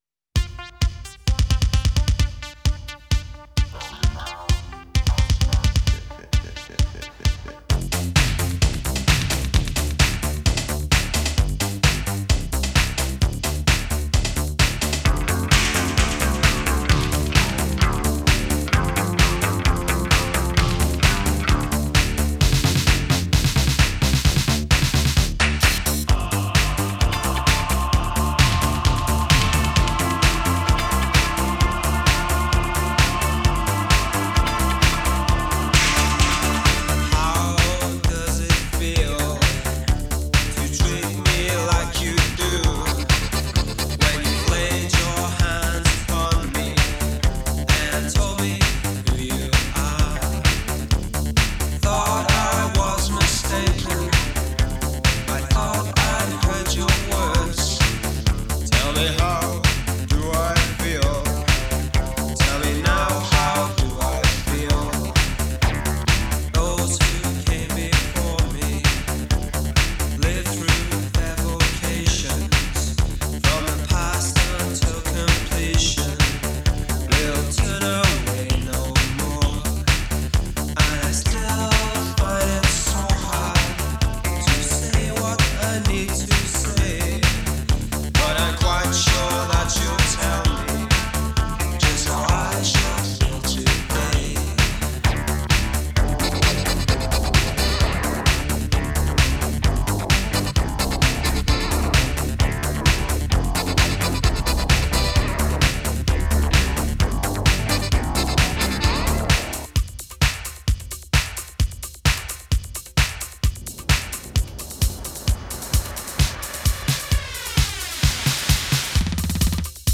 alternative dance Hi-NRG
آلترناتیو دنس سینث‌پاپ